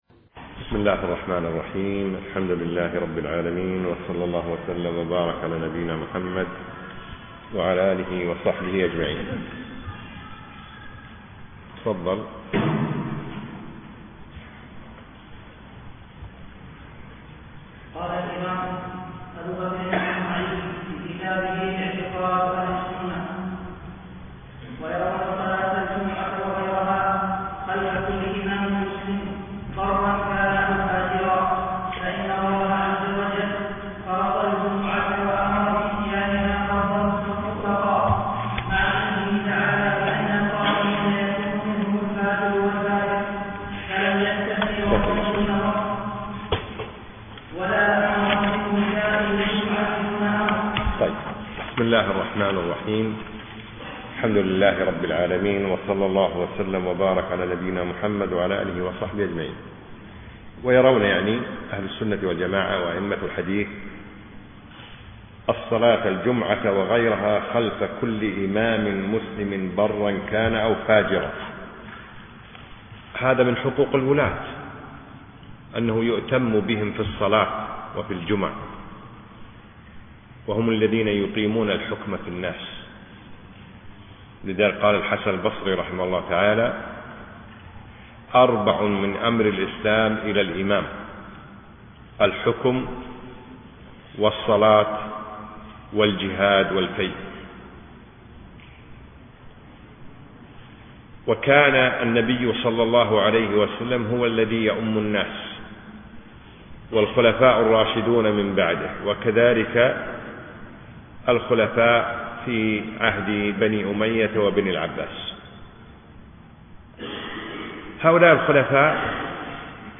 أقيمت الدورة في دولة الإمارات